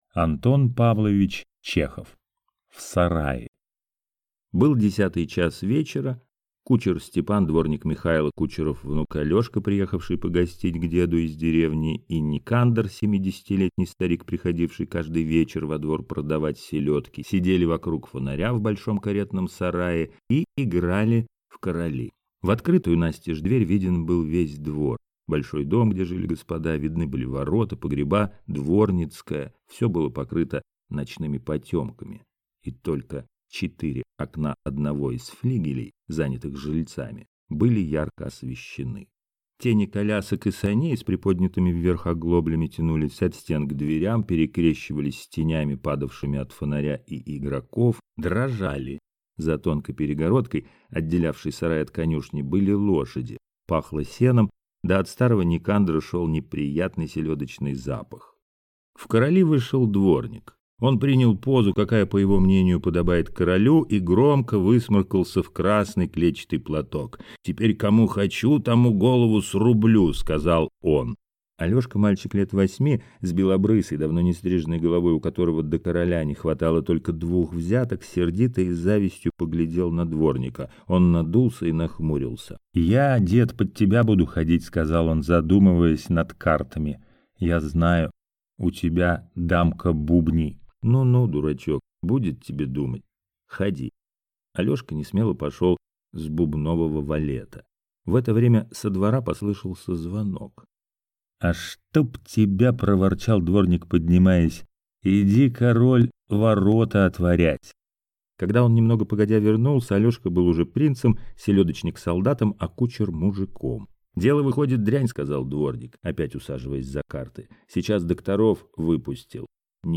Аудиокнига В сарае | Библиотека аудиокниг